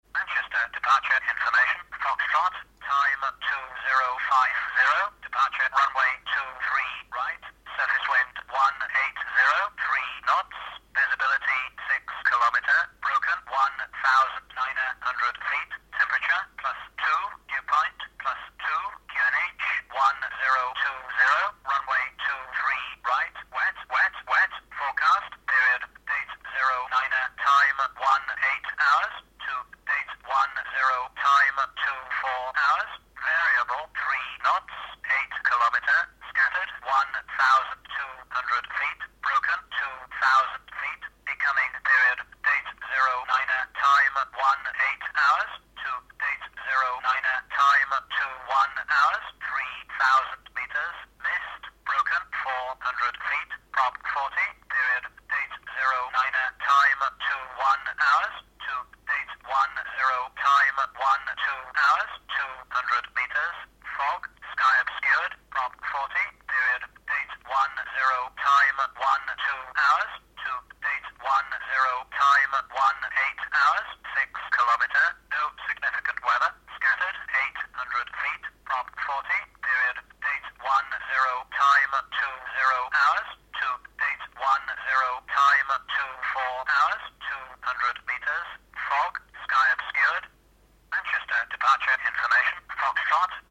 Les bases Le vocabulaire L'oreille Vol fictifs Le test L'oreille L’oreille doit pouvoir décrypter les messages prononcés avec divers accents et dans des conditions de transmission dégradées.
Manchester-Departure-ATIS-Jan2013.mp3